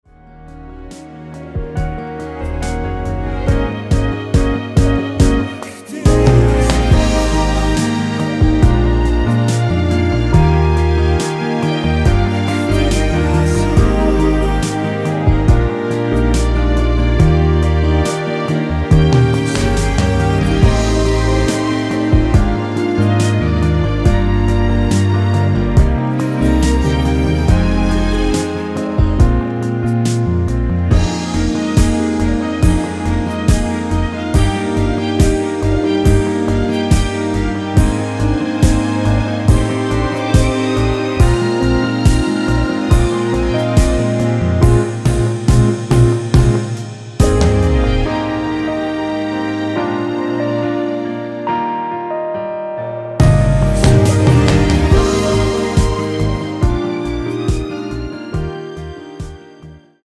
원키에서(-1)내린 코러스 포함된 MR입니다.
Db
앞부분30초, 뒷부분30초씩 편집해서 올려 드리고 있습니다.
중간에 음이 끈어지고 다시 나오는 이유는